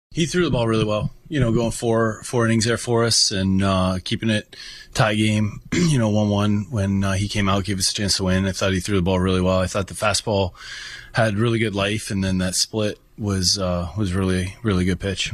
Kelly was happy with the work of Carmen Mlodzinski, who threw the first four innings in a spot start.